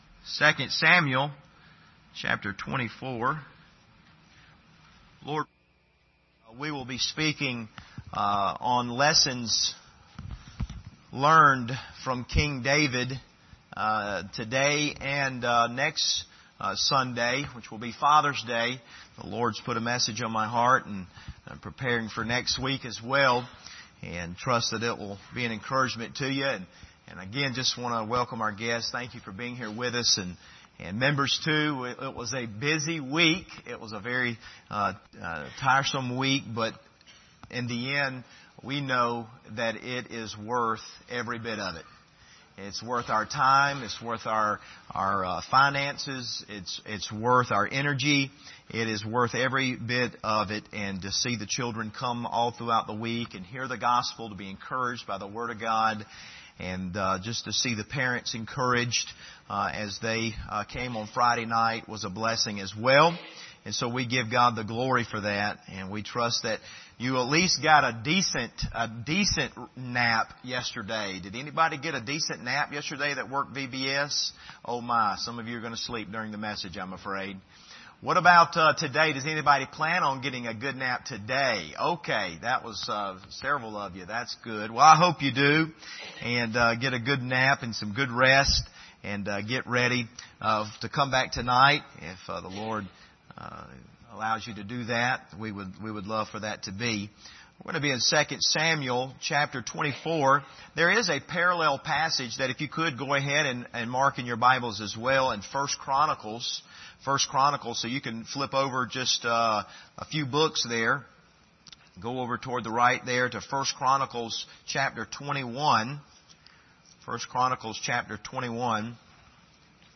Passage: 2 Samuel 24 Service Type: Sunday Morning